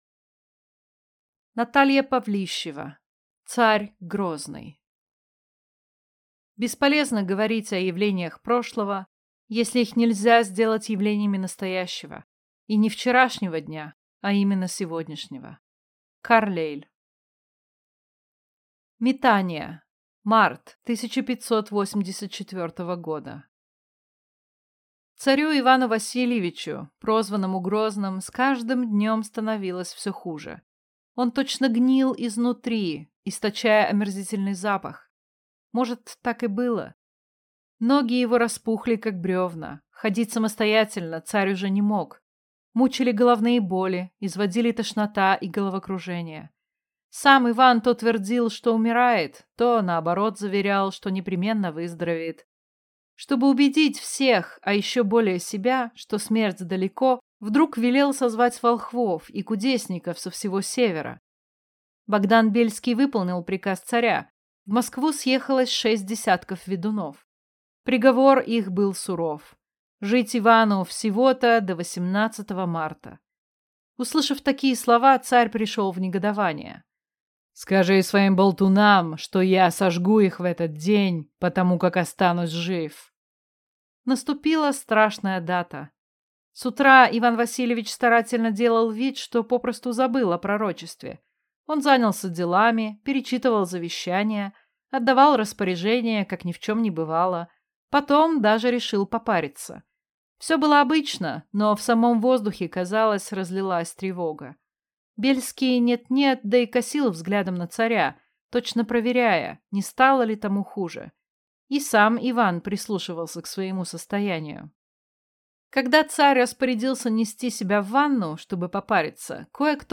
Аудиокнига Царь Грозный | Библиотека аудиокниг